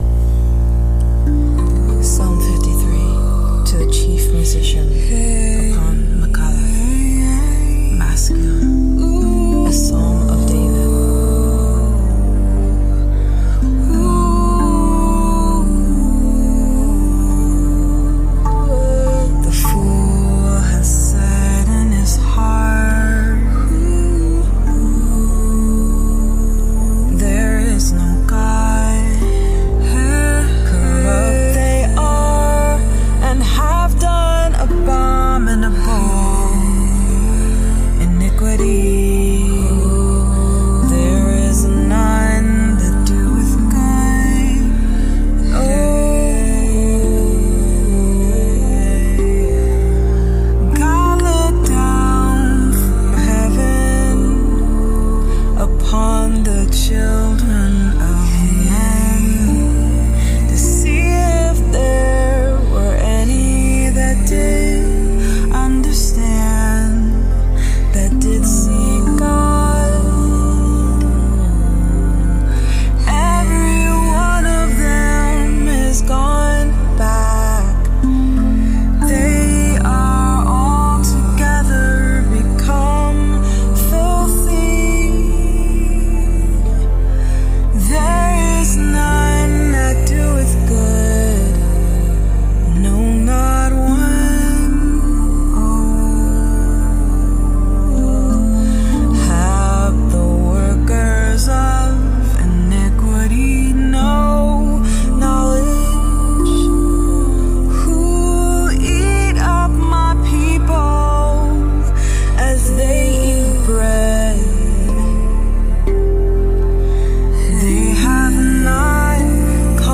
Psalm 53 Rav Vast & Beats Sessions